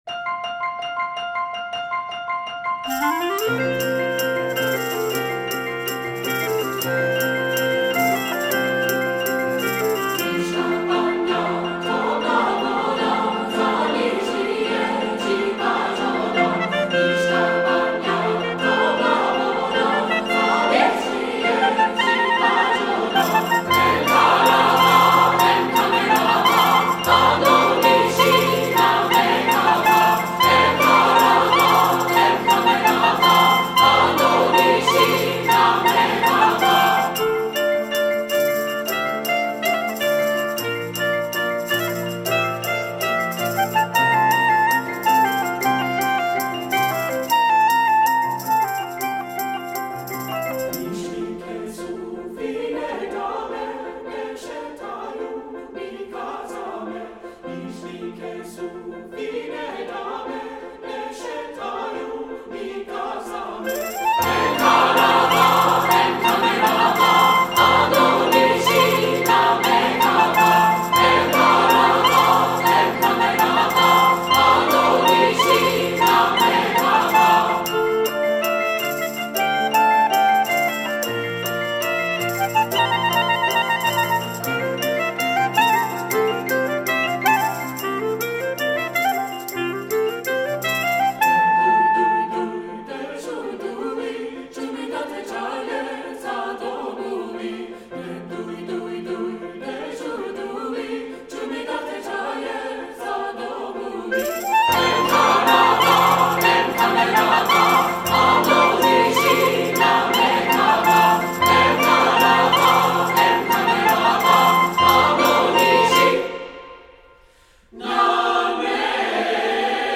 Composer: Serbian Gypsy Dance
Voicing: 2-Part